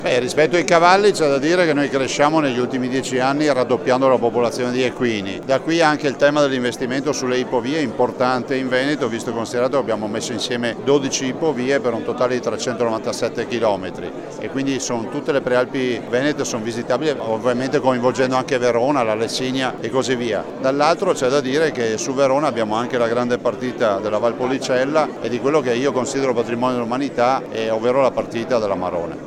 Presenti all’inaugurazione, intervistati dalla nostra corrispondente
il presidente della Regione Veneto Luca Zaia